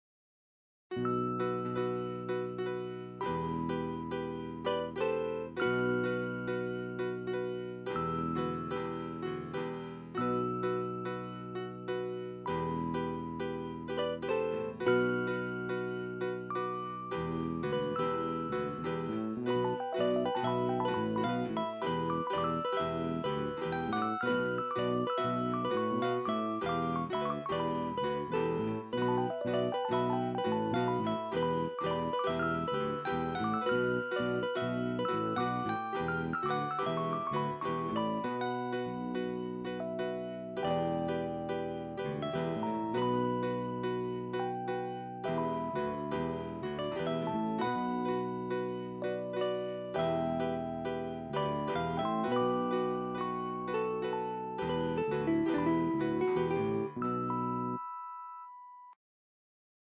Blues Style Fast Short
Quick Blues tune I made a while back
bluestylefast_0.ogg